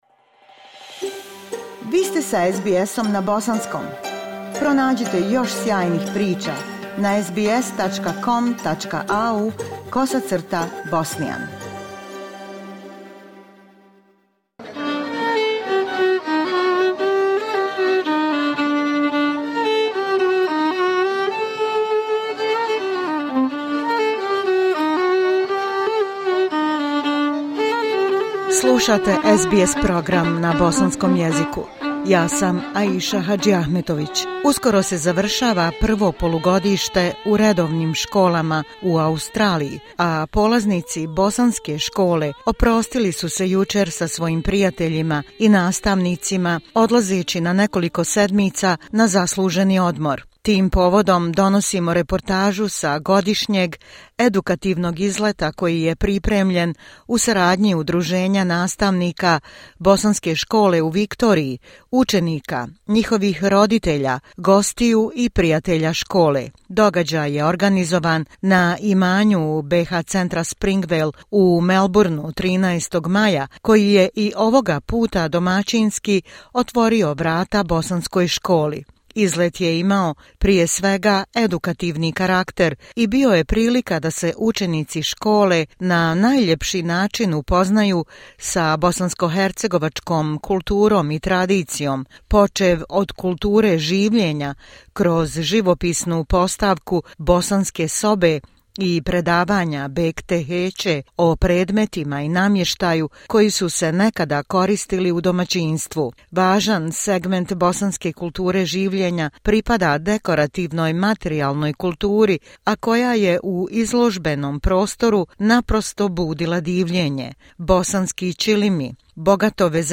Reportaža sa godišnjeg izleta koji je pripremljen u saradnji Udruženja nastavnika Bosanske škole u Viktoriji, učenika, njihovih roditelja, gostiju i prijatelja škole. Događaj je organizovan 13. maja na imanju BH Centra Springvale u Melbourneu.